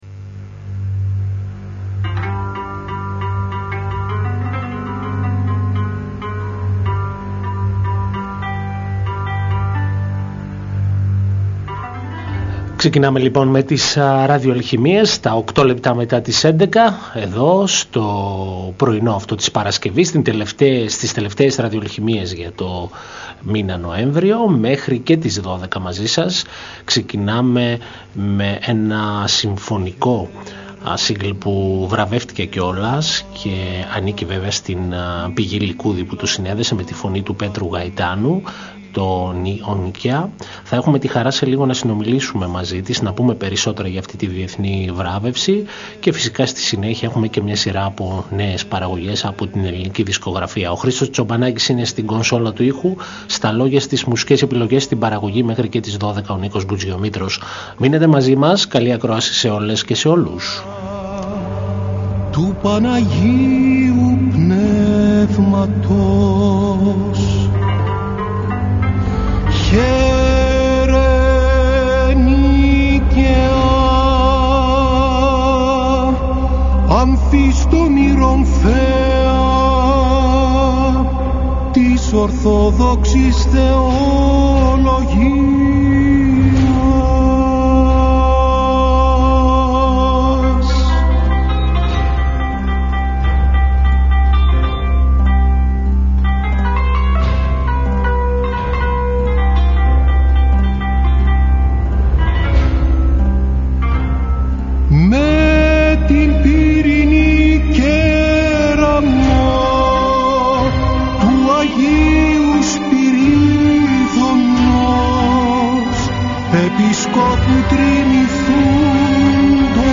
Επίσης παρουσιάζει τραγούδια από ολόφρεσκες ελληνικές δισκογραφικές παραγωγές.
Μια εκπομπή μουσικής και λόγου διανθισμένη με επιλογές από την ελληνική δισκογραφία.